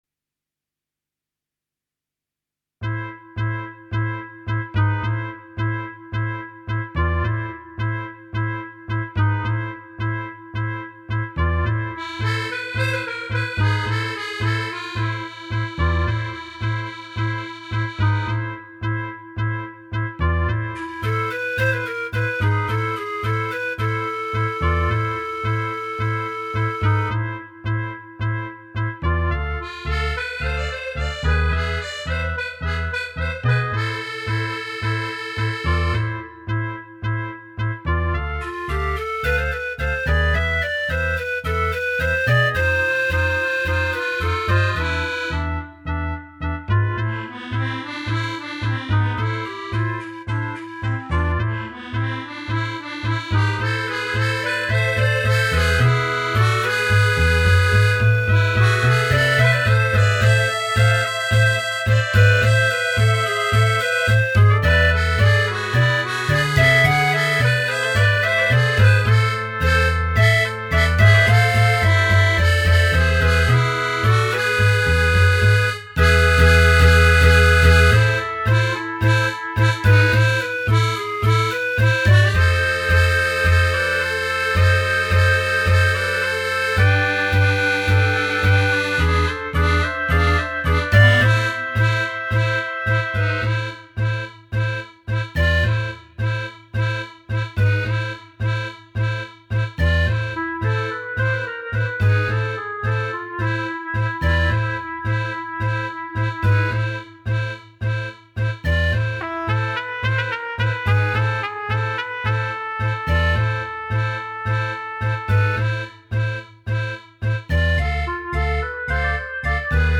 Instrumentation: C, Bb, Eb, Accordion, Bass
For five piece ensemble, this original composition
beautifully captures the hypnotic and pulsating rhythm